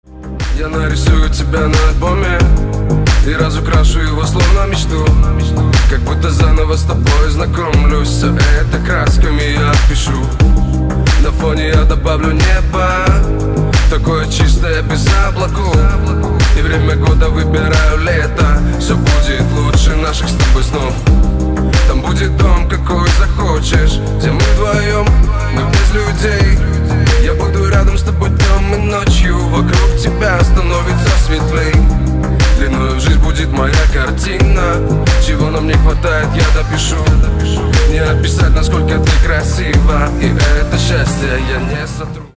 • Качество: 320, Stereo
мужской вокал
deep house
dance
Electronic
спокойные